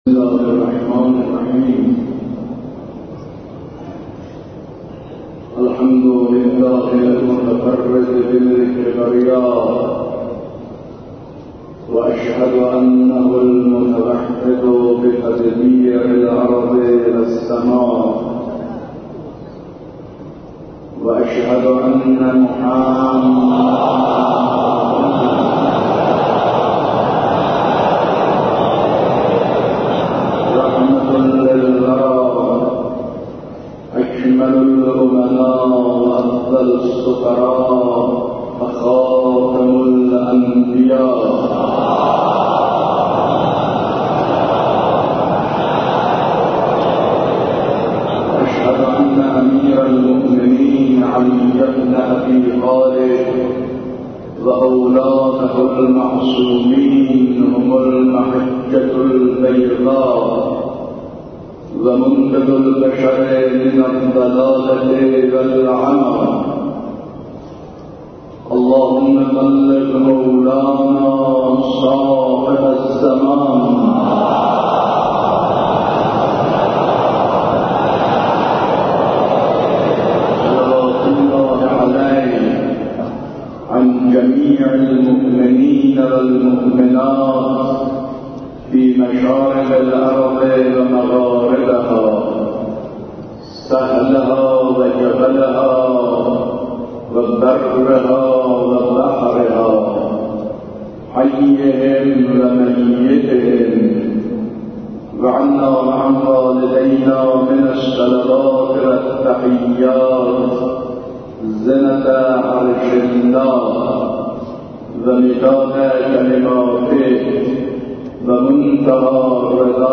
خطبه اول 23 خرداد.mp3